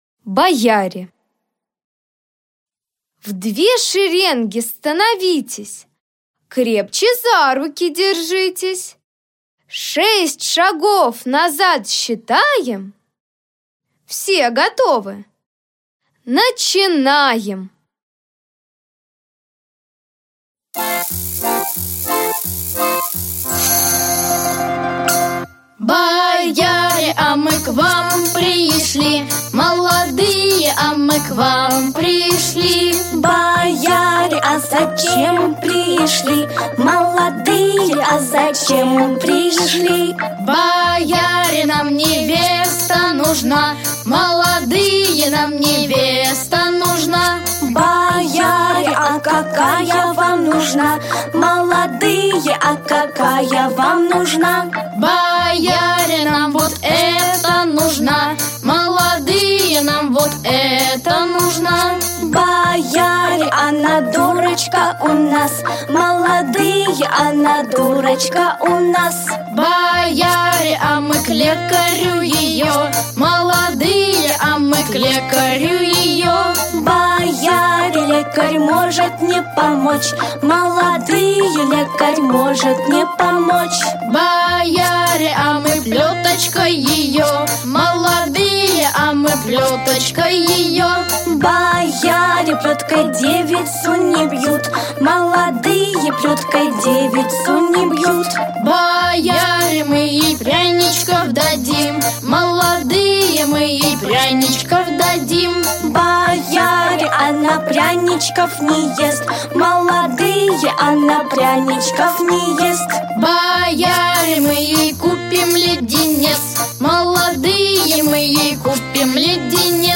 Аудиокнига Играй со мной. Подвижные музыкально-поэтические игры для детей | Библиотека аудиокниг